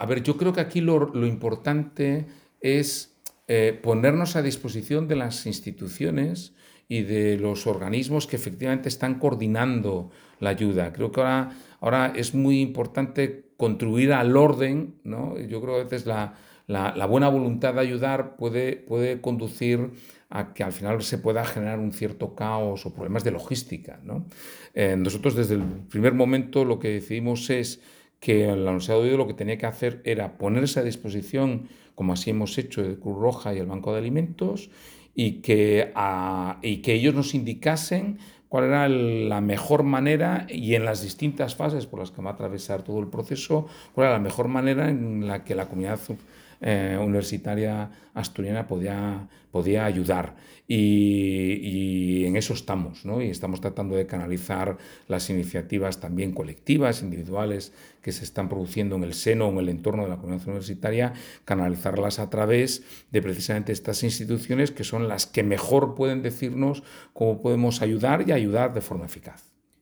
2024-11-05-NP-ayuda-valencia.docx (docx 175 kB) Audio de Ignacio Villaverde, rector de la Universidad de Oviedo, sobre la campaña de solidaridad con los damnificados por las inundaciones en Valencia (mp3 2,1 MB)